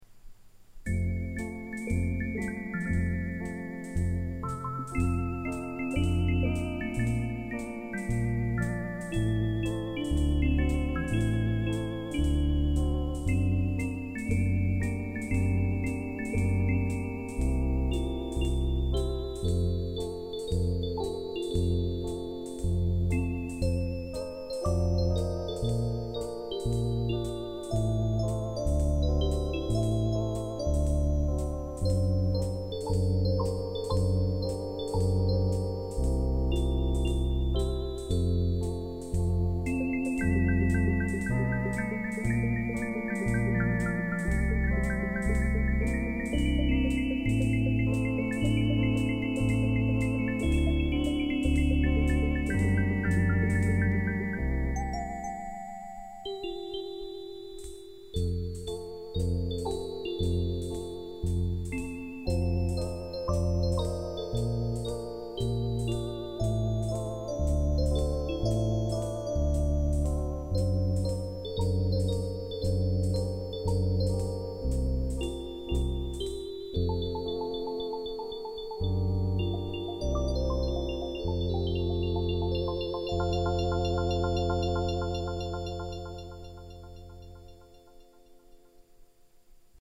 Post tonewheel – Concorde
Ebb Tide — Once upon a Time in the West (x2) — Raindrops keep falling on my head — Mozart: petite musique de nuit. Practice tunes from a slow learner, 1980.